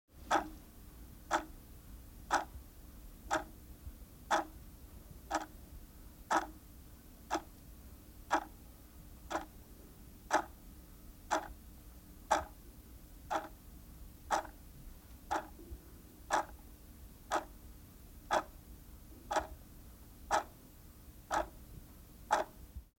دانلود صدای ساعت 6 از ساعد نیوز با لینک مستقیم و کیفیت بالا
جلوه های صوتی